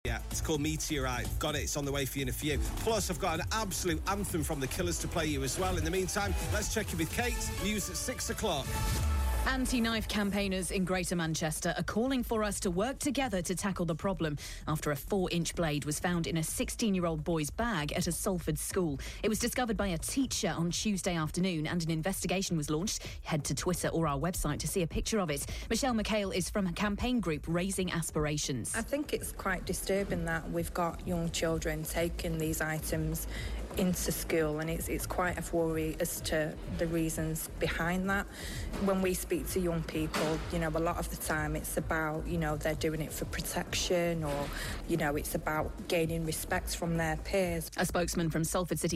Campaigner speaks to Key news after boy takes 'Rambo style' knife into school